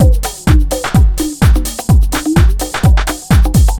127BEAT1 2-L.wav